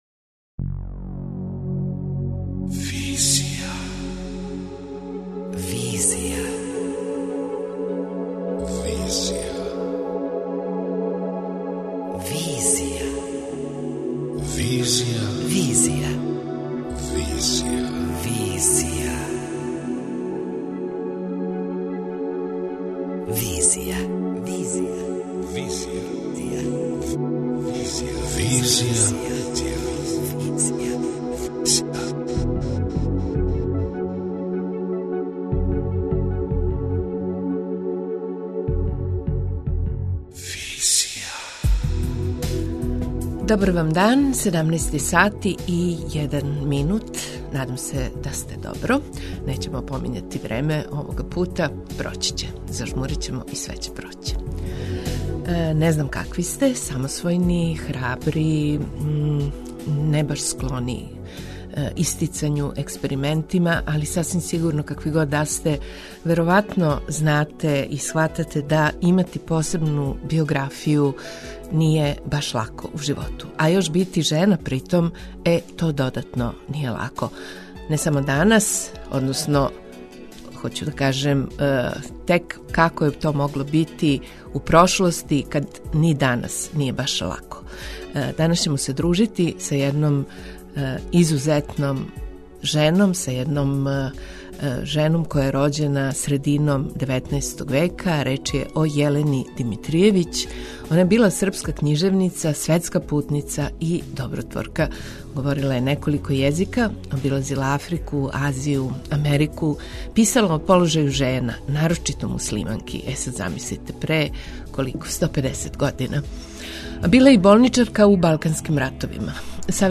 преузми : 27.28 MB Визија Autor: Београд 202 Социо-културолошки магазин, који прати савремене друштвене феномене.